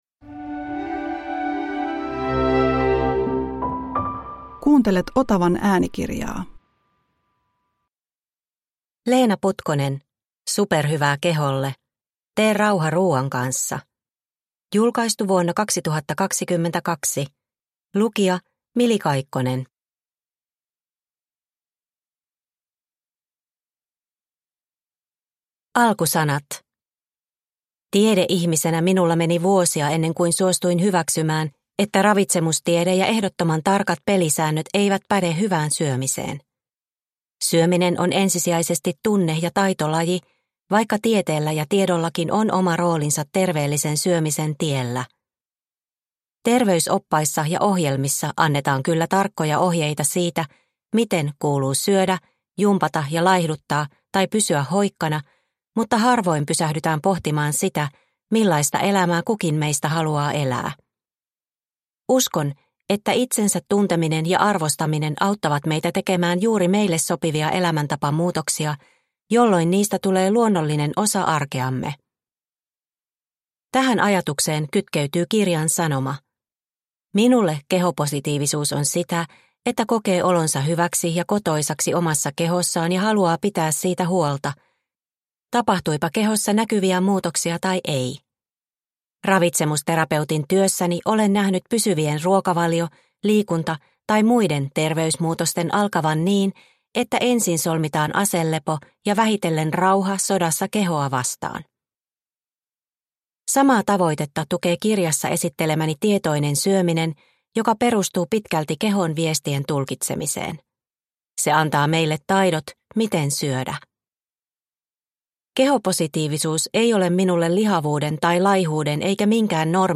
Superhyvää keholle – Ljudbok – Laddas ner